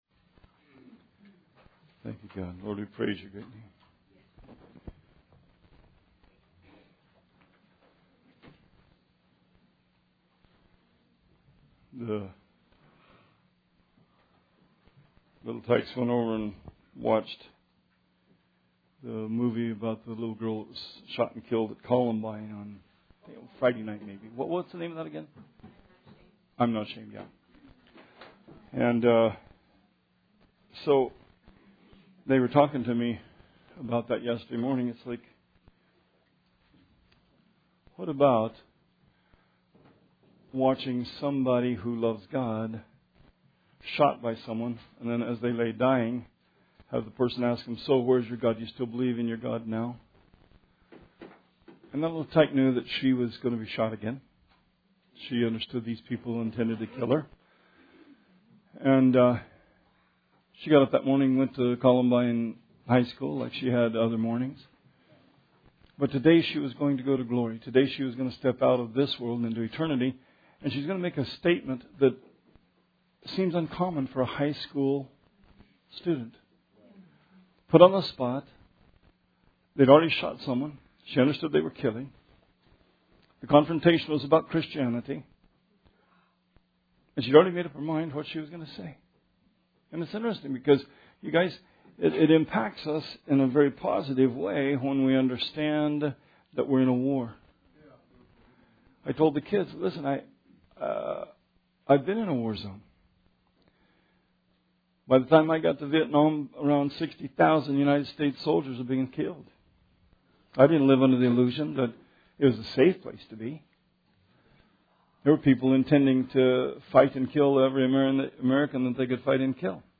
Sermon 2/5/17 – RR Archives